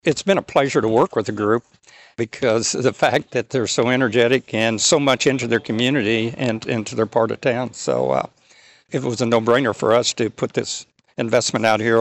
During a dedication event Monday afternoon, City of Emporia Vice-Mayor Danny Giefer says he is proud of the new addition and the cooperative efforts that brought it to the community.